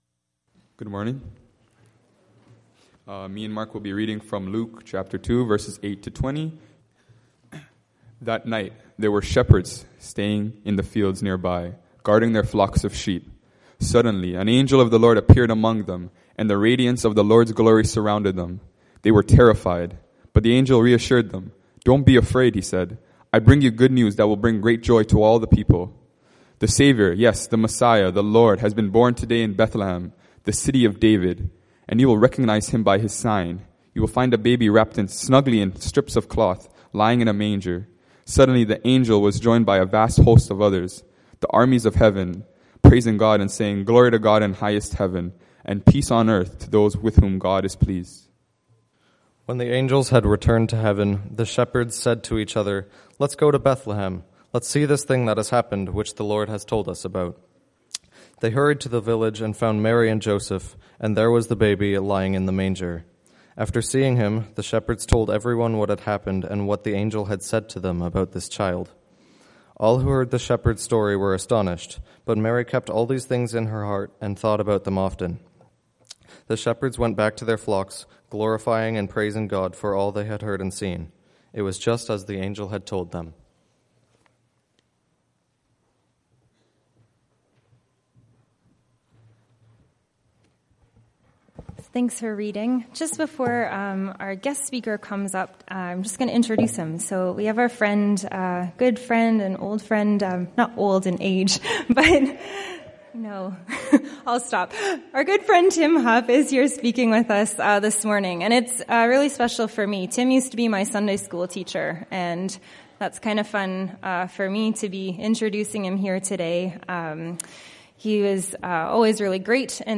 Christmasing Always Luke 2:8-20 Guest Speaker November 29
sermon_nov29.mp3